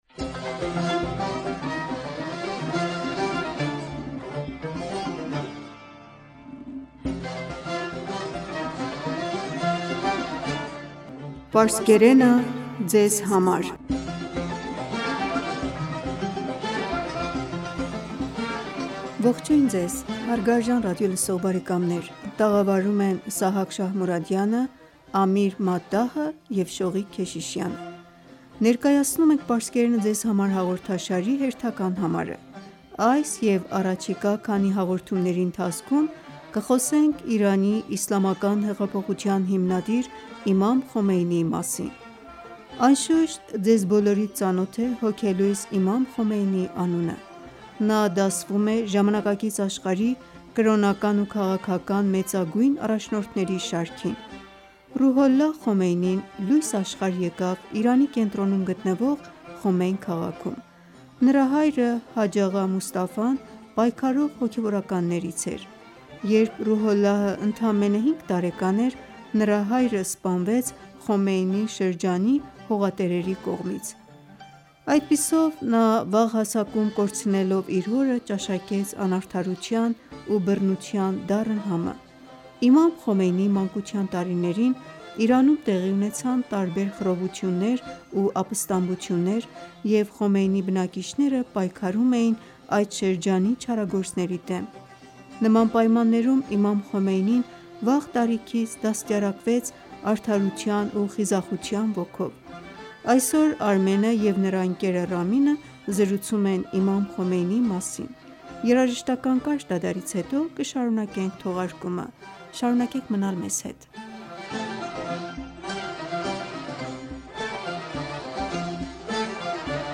Ողջույն Ձեզ հարգարժան ռադիոլսող բարեկամներ: Ներկայացնում ենք Պարսկերենը Ձեզ համար հաղորդաշարի հերթական համարը: Այս և առաջիկա քանի հաղորդումների ընթացքում կ...
Երաժշտական կարճ դադարից հետո կշարունակենք թողարկումը: